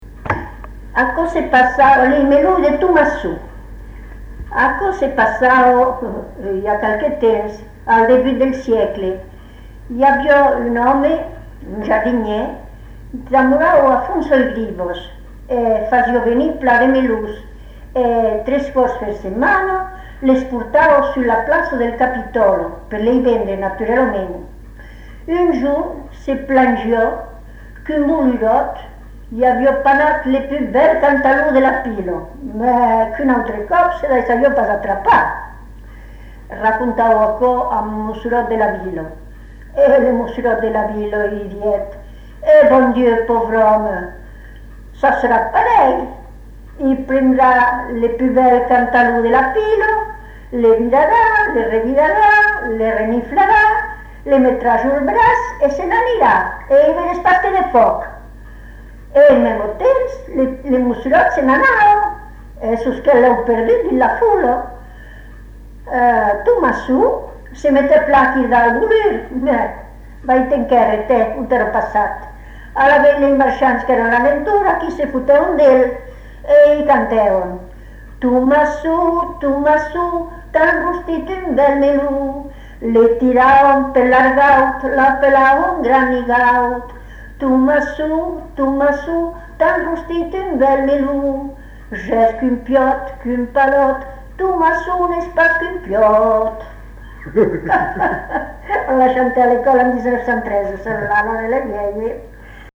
Lieu : Bessières
Genre : conte-légende-récit
Effectif : 1
Type de voix : voix de femme
Production du son : parlé